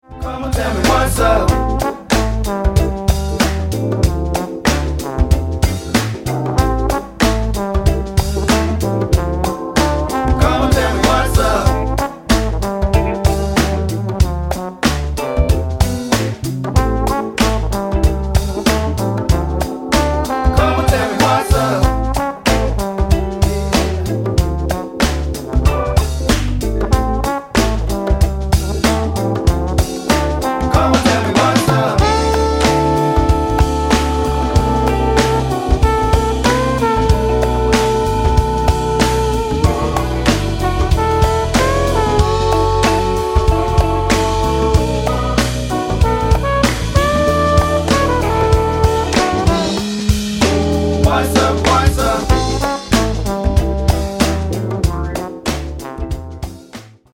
C'est effectivement un son fluté, sans 5-1/4 il me semble.
tromboniste funk
par moment j'entends qu'il n'a plus le vibrato,
avec la leslie lente/rapide en alternance